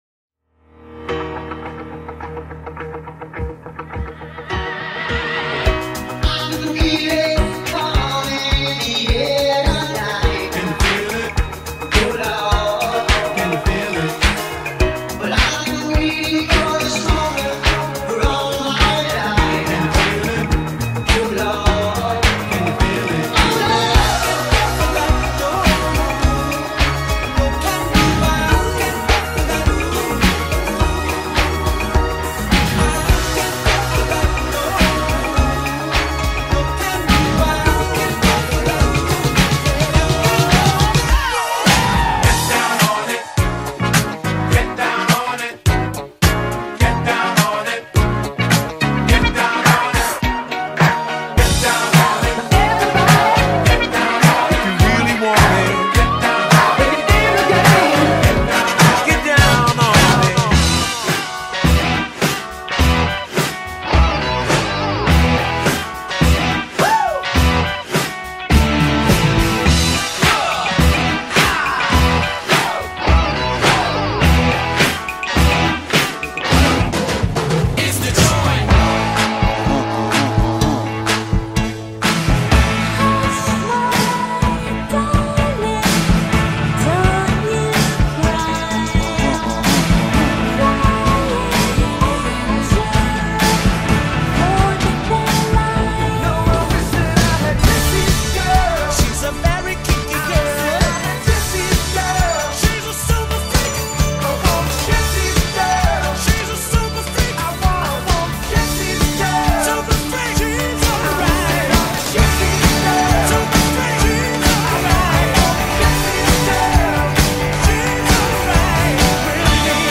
80s music mix.